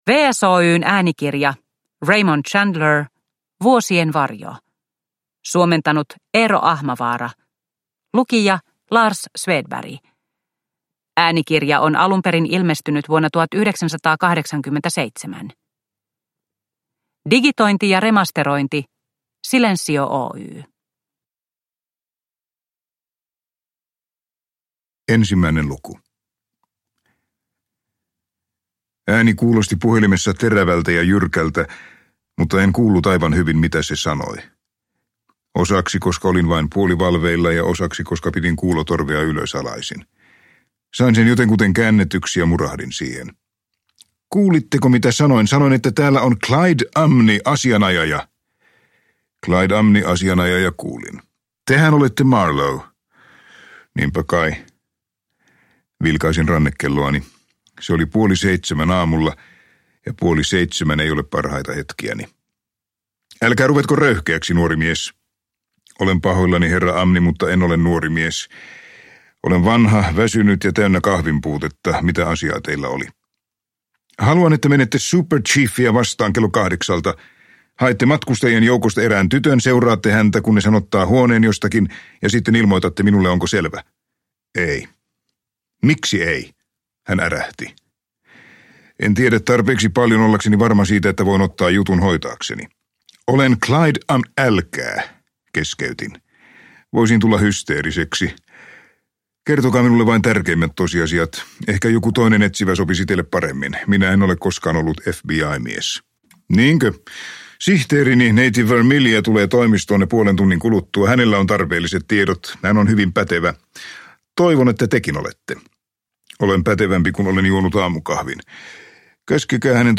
Vuosien varjo – Ljudbok – Laddas ner